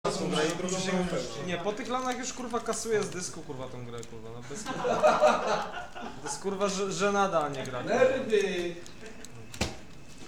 psych_whine.mp3